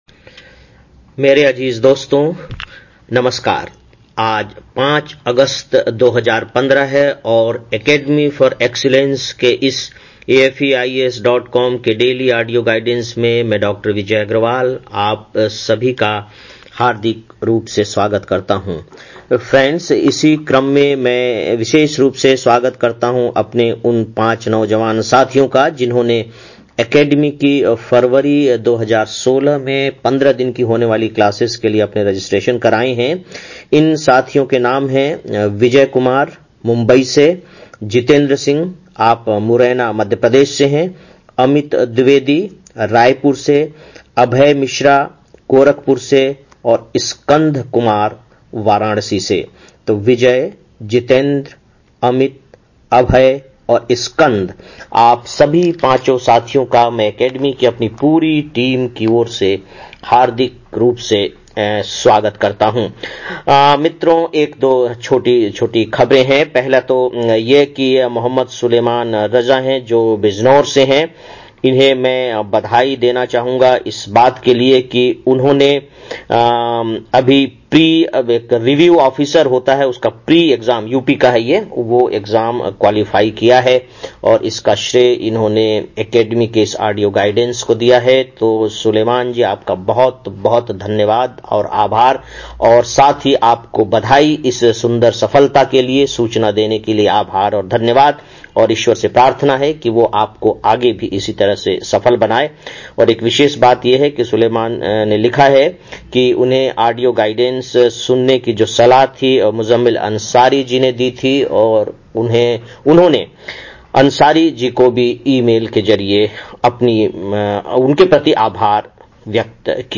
05-08-15 (Daily Audio Lecture) - AFEIAS